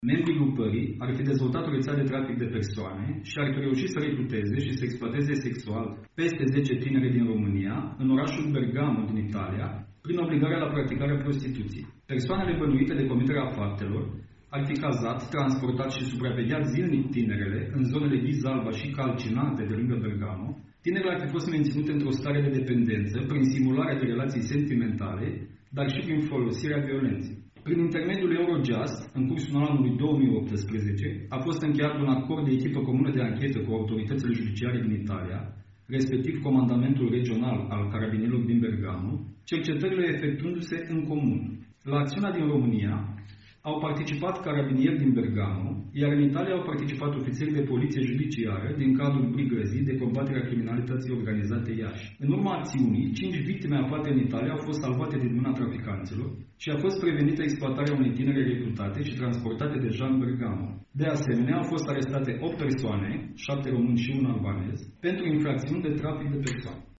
5 persoane au fost salvate în localitatea italiană Bergamo, femei care se aflau într-o situaţie de semi-detenţie, obligate fiind să se prostitueze, a declarat un ofiţer din cadrul Brigăzii de Combatere a Criminalităţii Organizate de la Iaşi: